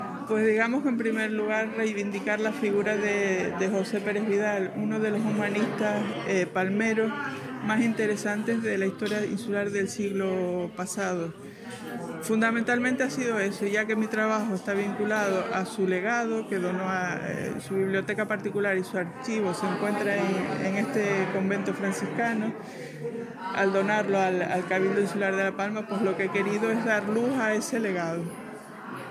El Patio de los Naranjos del Museo Insular de La Palma acogió la presentación del libro ‘«He recibido su carta del 14, grata y muy amable como todas las suyas»: correspondencia galdosiana de José Pérez Vidal (1907-1990)’ .